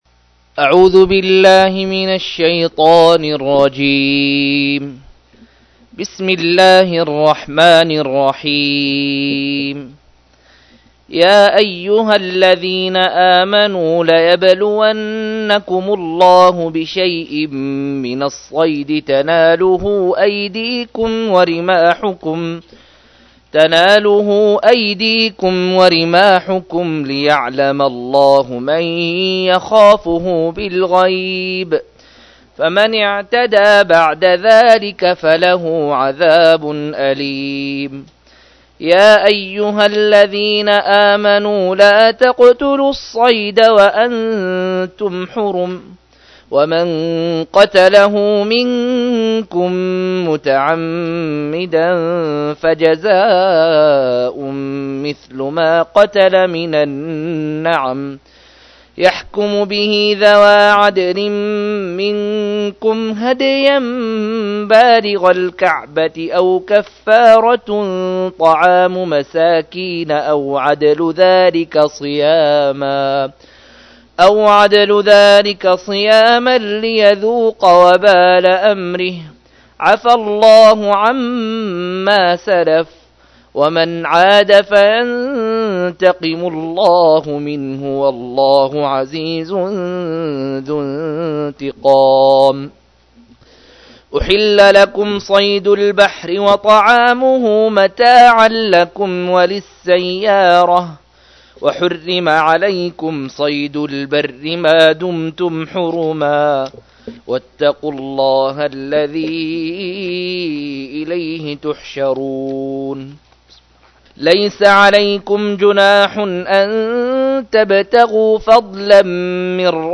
الإلمام ببعض آيات الأحكام لابن عثيمين رحمه الله – قراءة وتعليق لتفسير آيات الحج –